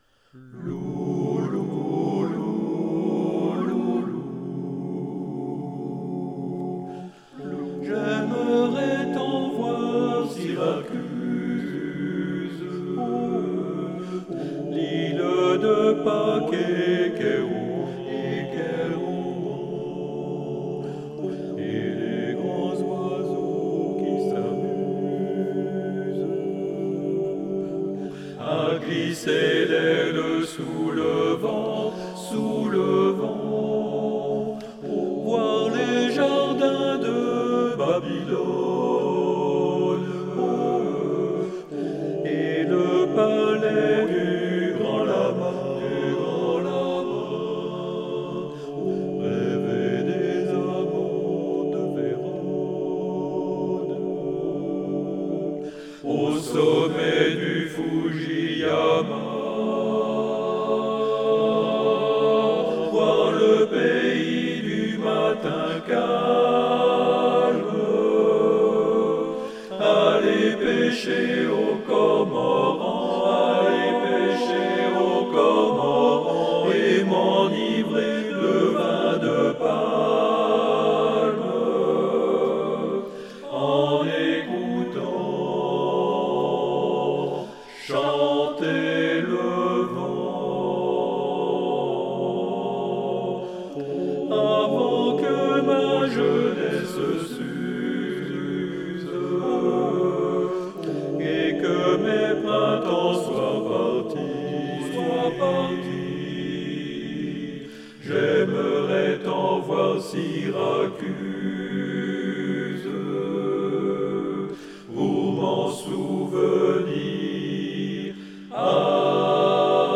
harmonisation
à 4 voix